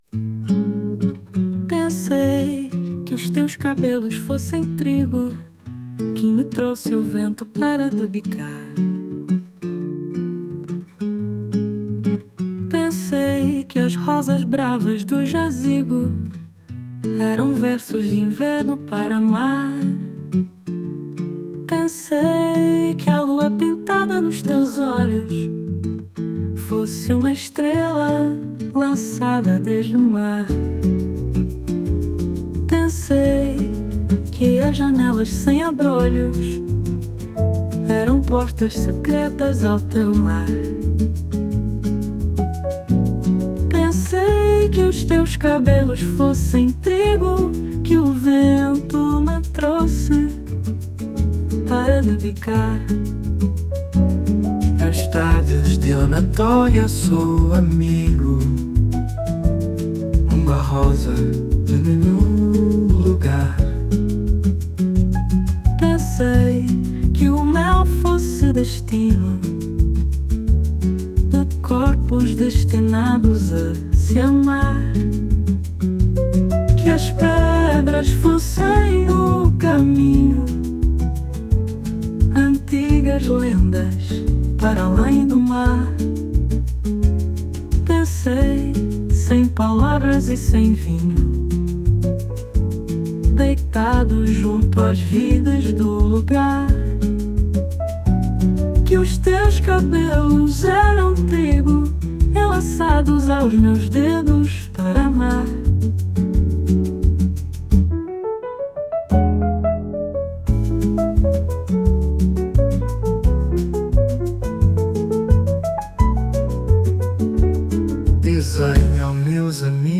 composição de bossa nova criada com inteligência artificial